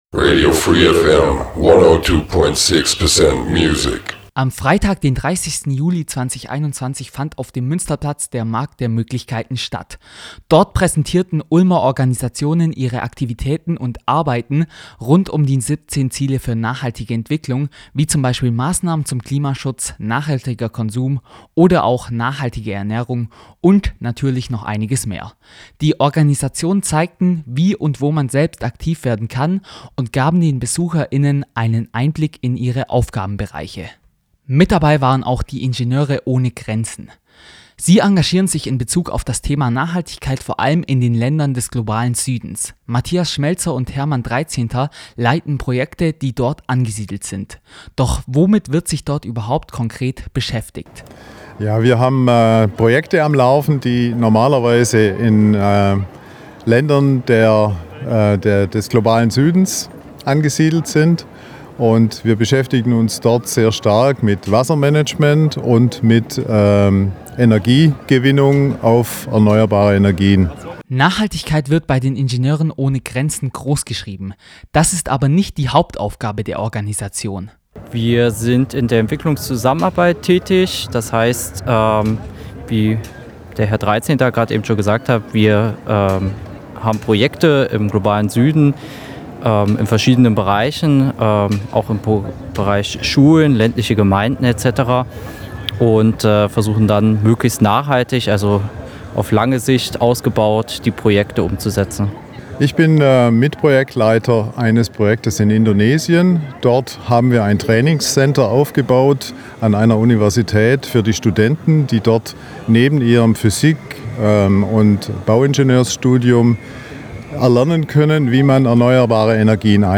Am Freitag, den 30. Juli 2021 fand in Ulm der Markt der Möglichkeiten statt.
Mehr dazu erfahrt ihr im Gespräch